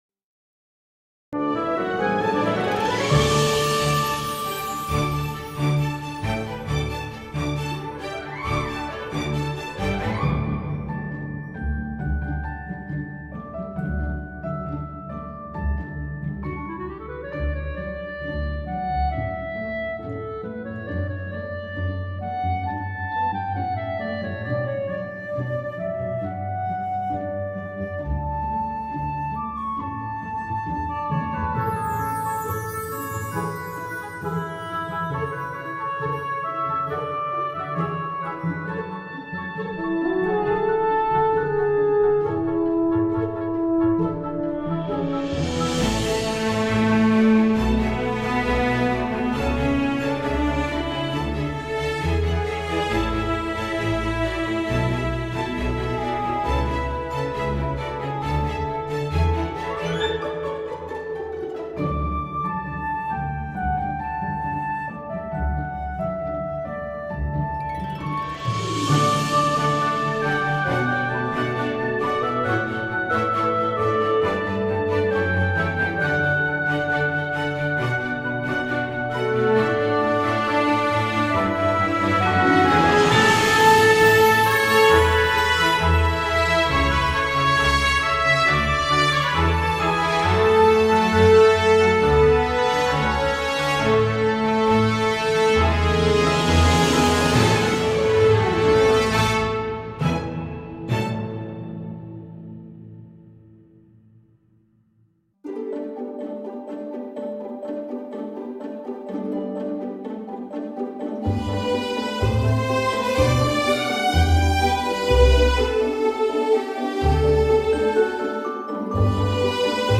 musica-bosque-magico-instrumentalmusica-instrumental-relajante.mp3